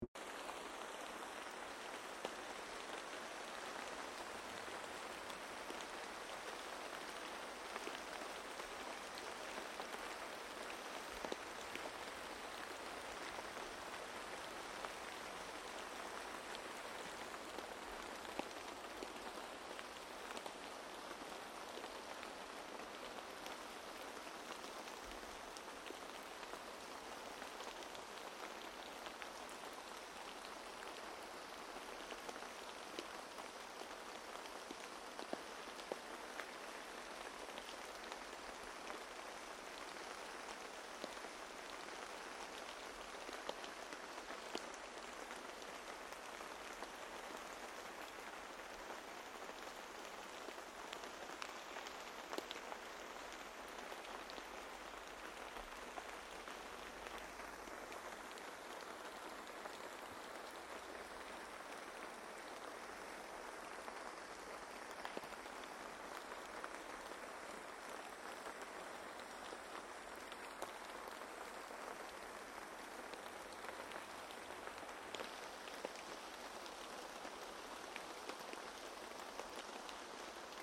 Sateen ropinaa